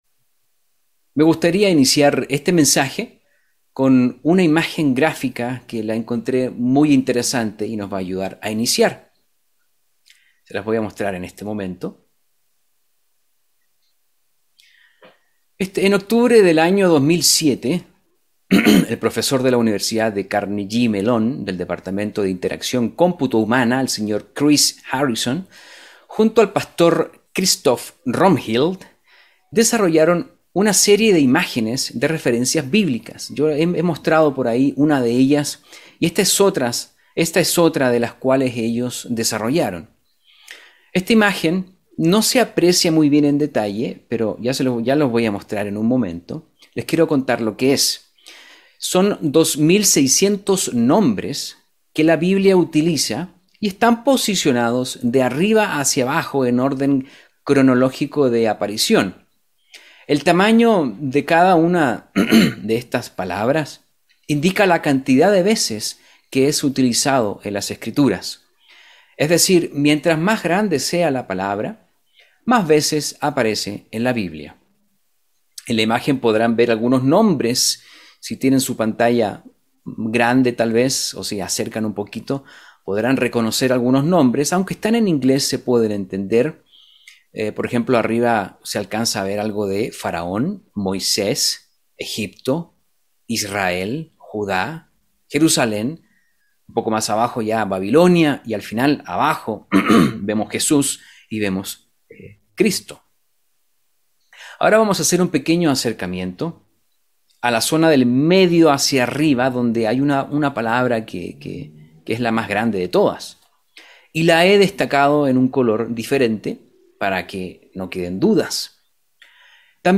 Mensaje entregado el 7 de septiembre de 2021.